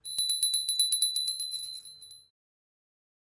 描述：小编钟